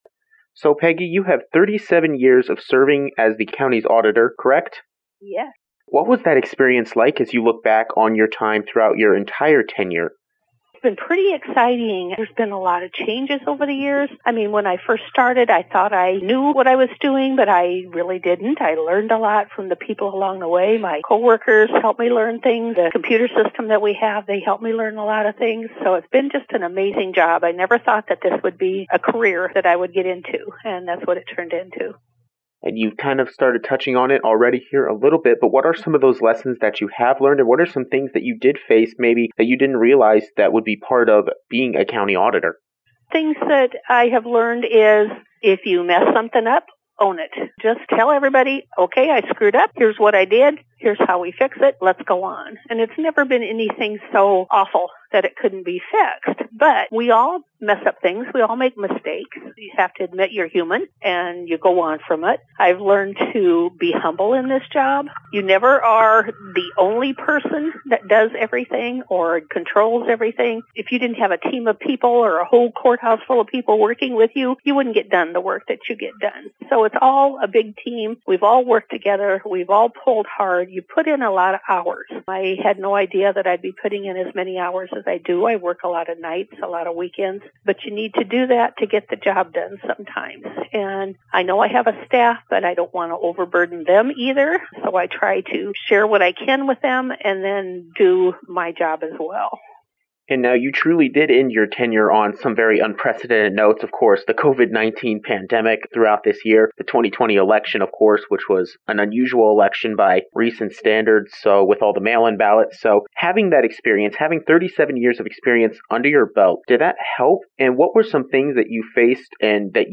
A final interview with Peggy Rice
Rice-retirement-interview_FULL.mp3